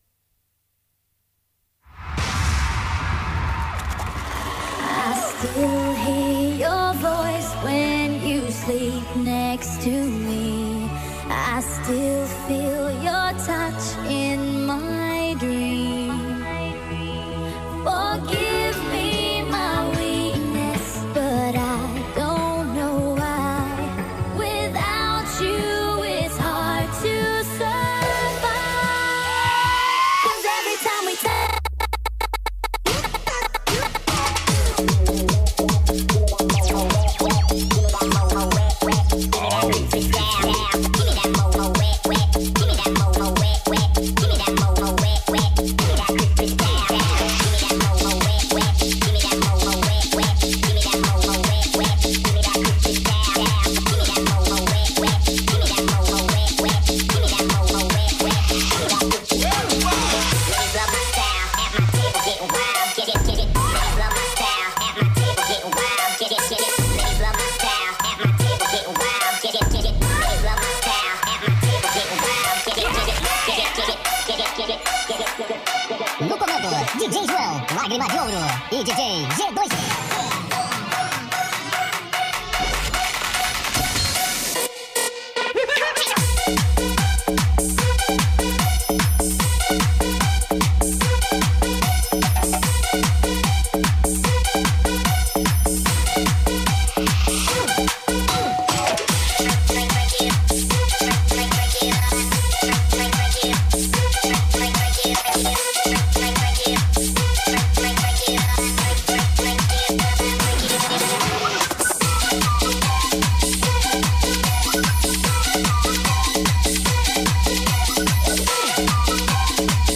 Bruits de Lune, c’est une émission mensuelle de radio en direct de La Lune des Pirates avec des concerts d’artistes amateurs locaux, des chroniques, des interviews et des actus culturelles !